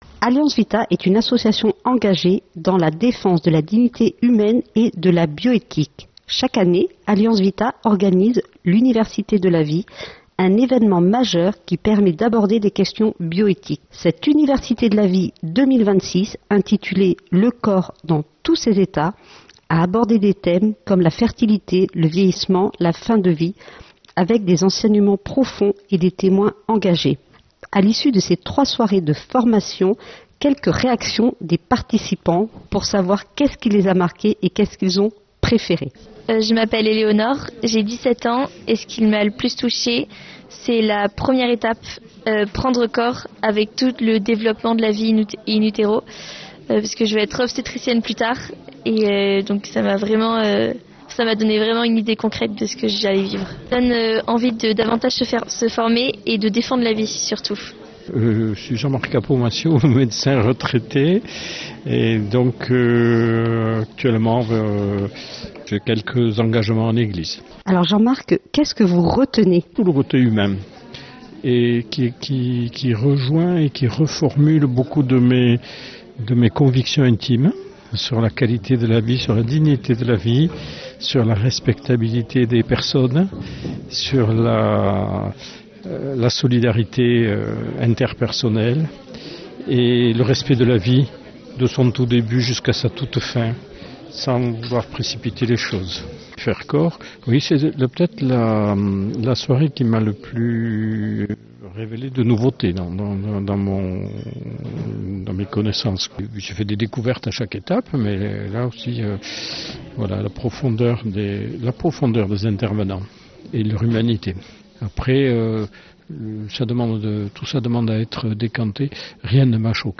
Témoignage université de la vie